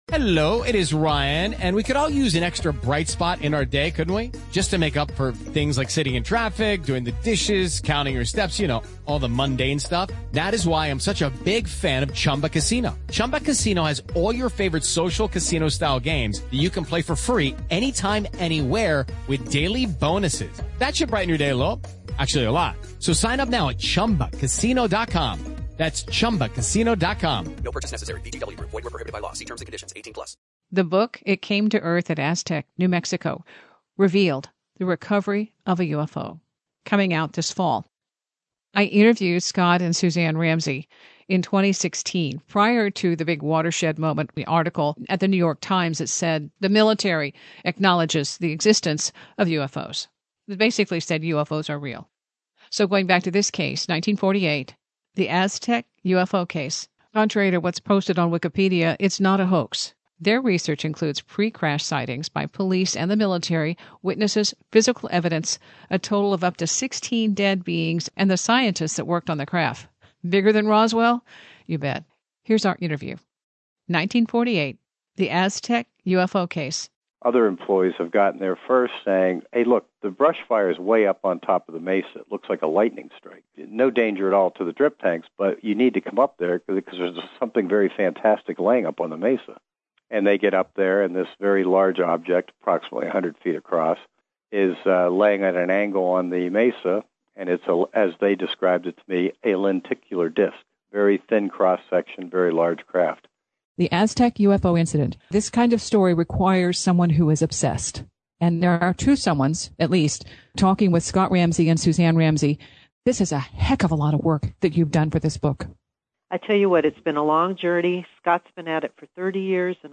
In anticipation of the new book release, this is an update of our 2016 interview. Edited {to remove music} and refreshed, our interview was prior to the 2017 paradigm shifting New York Times article.